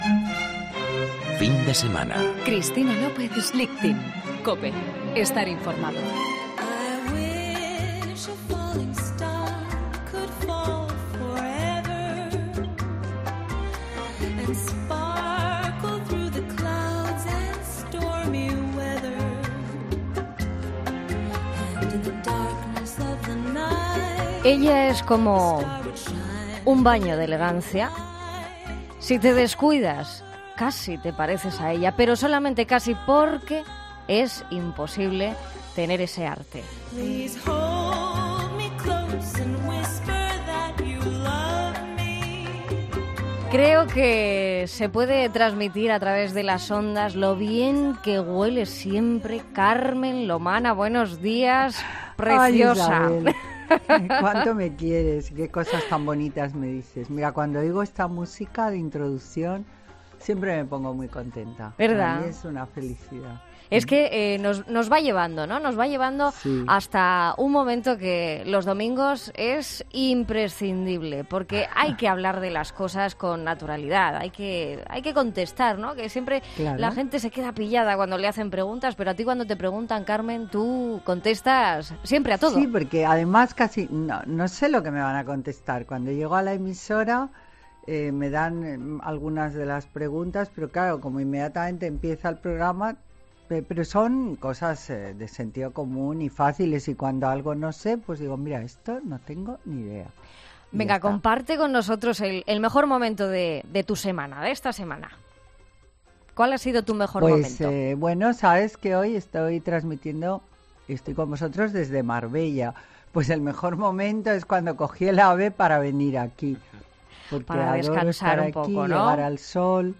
AUDIO: Las respuestas de Carmen Lomana a los oyentes.